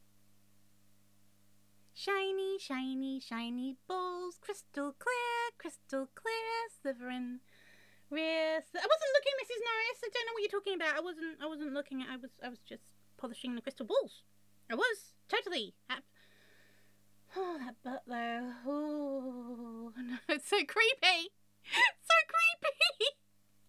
Tags: outtakes